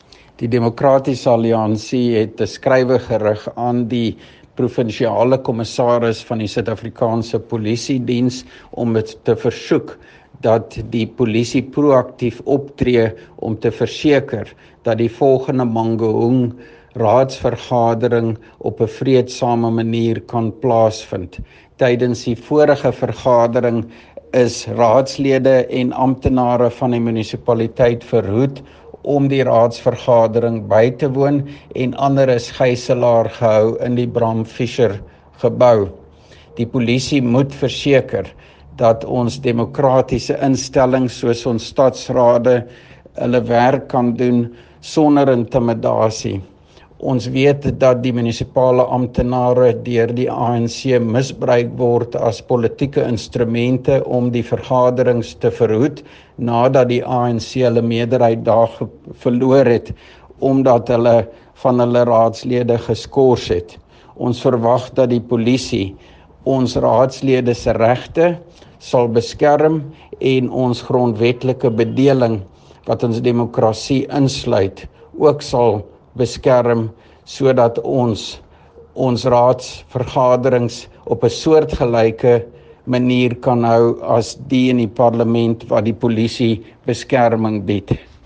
Afrikaans soundbites by Dr Roy Jankielsohn MPL.